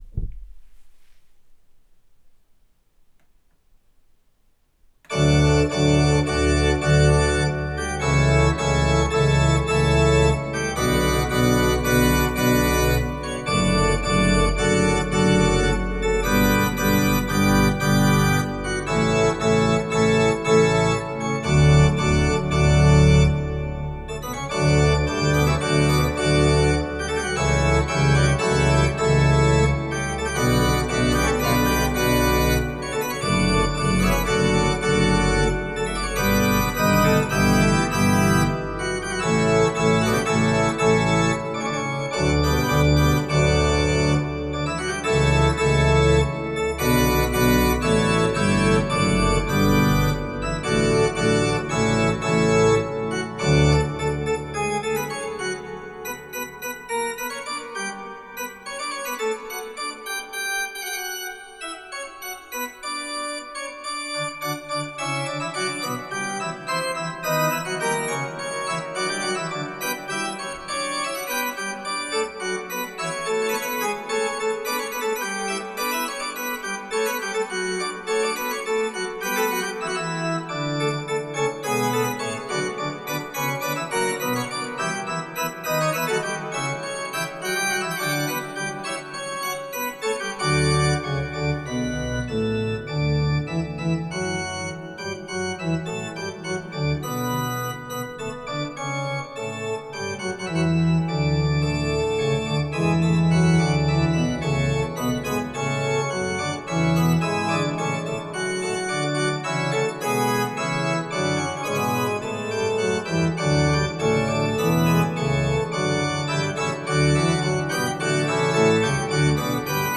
Studierende der Fachbereiche Kirchenmusik und Komposition der Musikhochschulen Frankfurt und Mainz haben kurze Orgelstücke komponiert, die am 12. September 2021 in vielen Kirchen in Hessen und Rheinland-Pfalz uraufgeführt wurden.
Die Kompositionen sind freie, nicht choralgebundene Stücke, mit einer Spieldauer von 3-4 Minuten, auf einer einmanualigen Orgel mit Pedal darstellbar und vom Schwierigkeitsgrad her auch für nebenberufliche Organist*innen mit C- oder D-Prüfung spielbar.
Präludium und Fuge in D-Dur